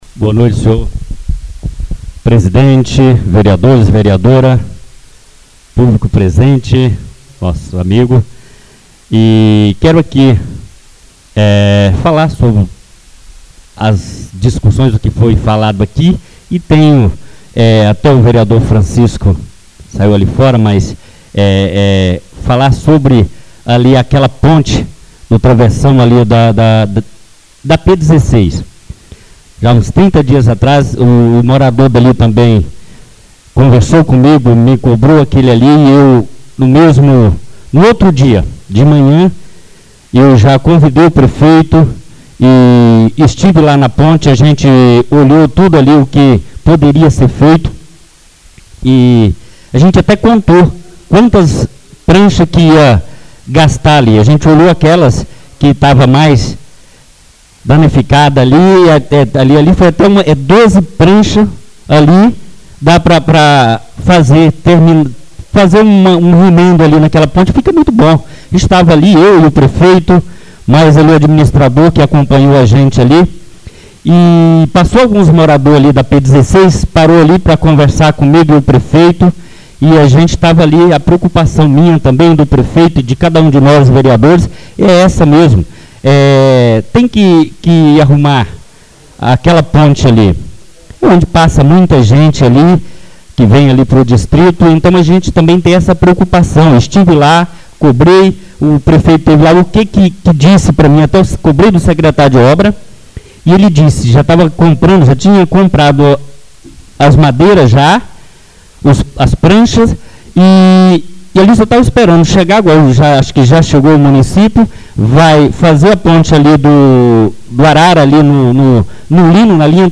Oradores das Explicações Pessoais (26ª Ordinária da 3ª Sessão Legislativa da 6ª Legislatura)